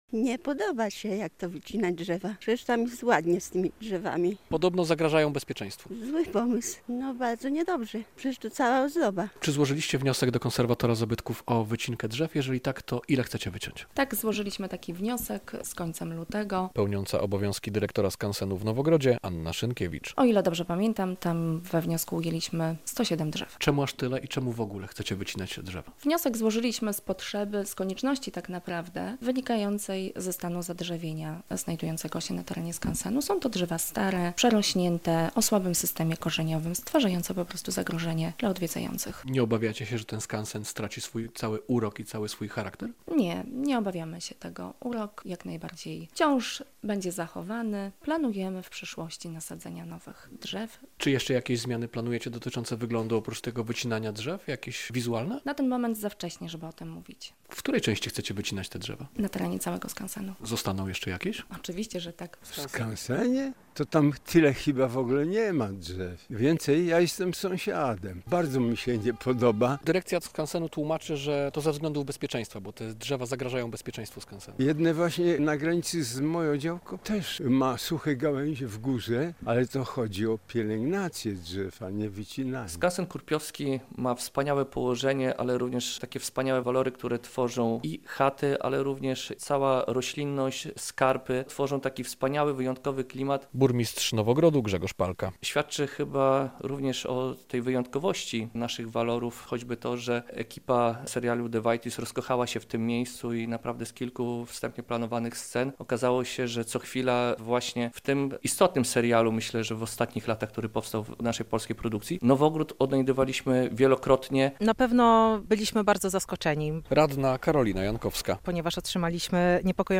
Sprawa wycinki drzew w skansenie - relacja